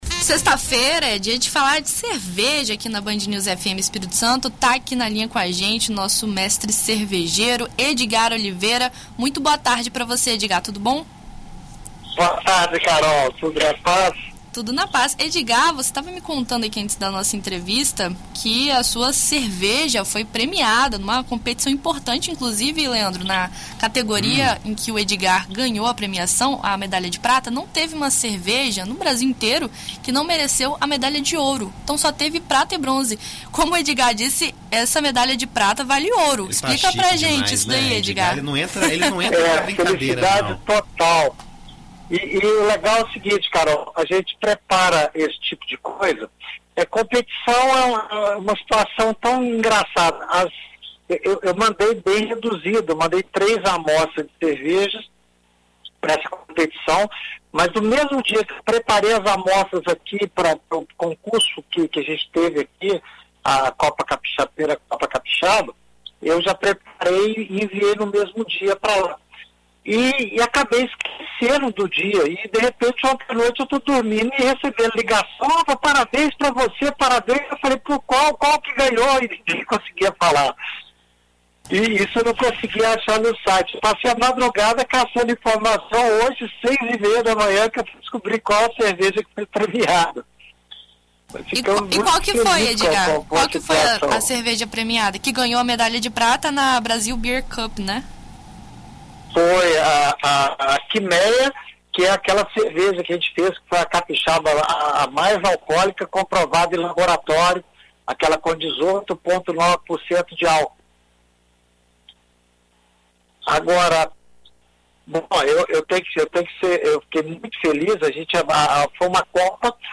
Em entrevista à BandNews FM ES